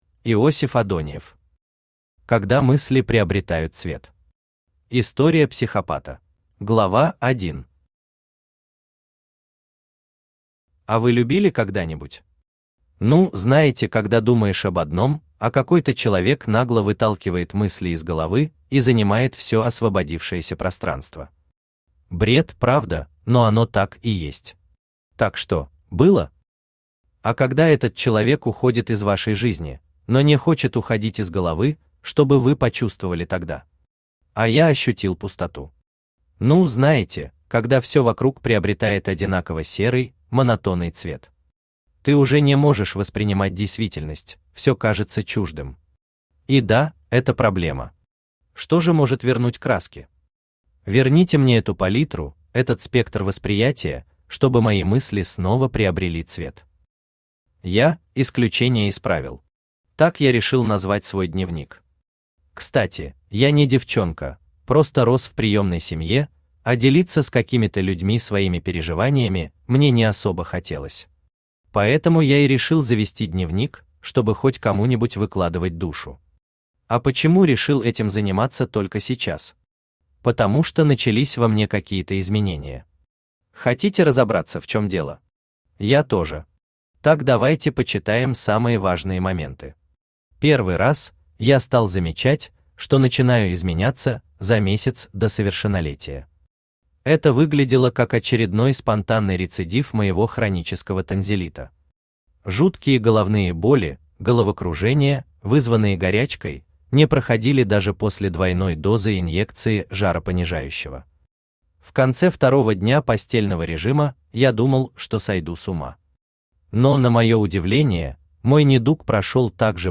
История психопата Автор Иосиф Александрович Адоньев Читает аудиокнигу Авточтец ЛитРес.